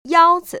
[yāo‧zi]